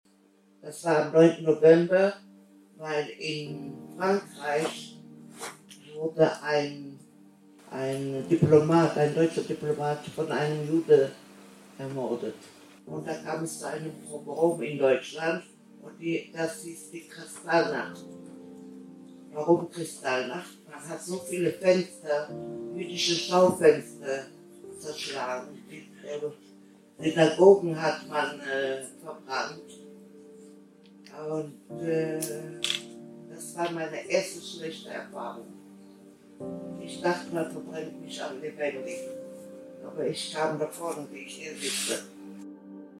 Zeitzeugeninterview